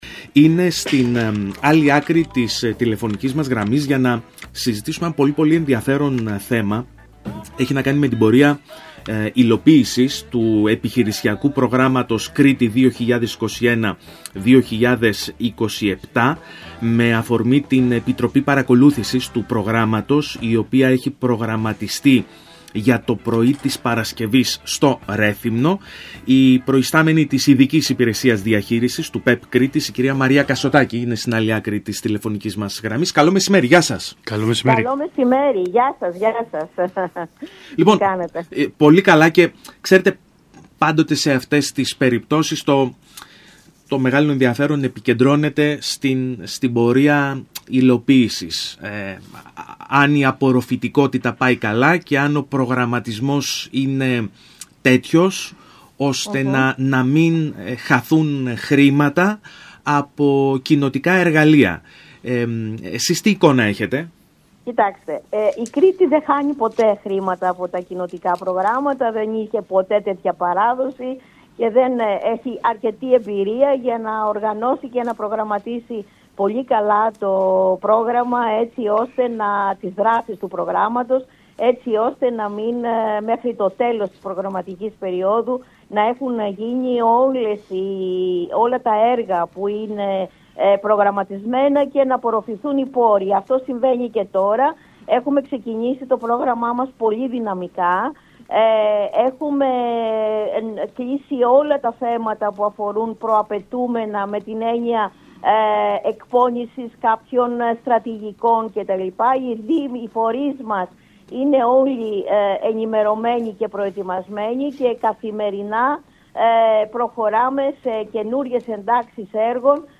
Αισιόδοξο στίγμα για την πορεία υλοποίησης του προγράμματος “Κρήτη 2021 – 2027” έδωσε, μιλώντας στο ραδιόφωνο του ΣΚΑΪ Κρήτης 92,1